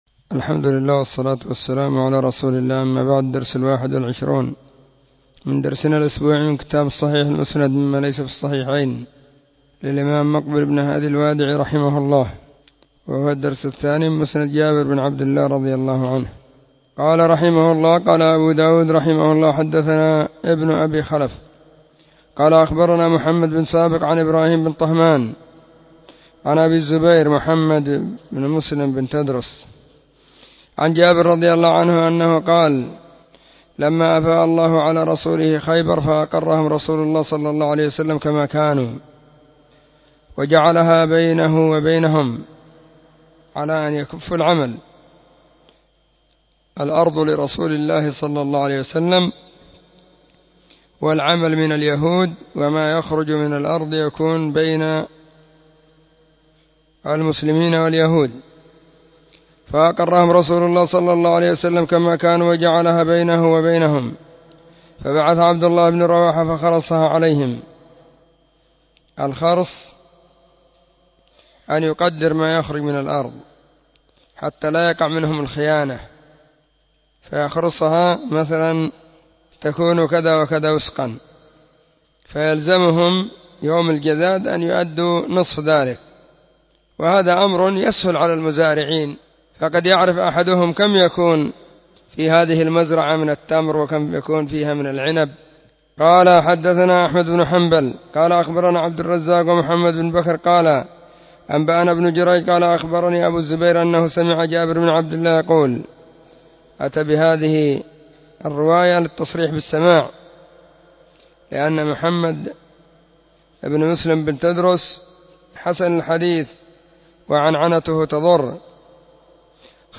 خميس -} 📢مسجد الصحابة – بالغيضة – المهرة، اليمن حرسها الله.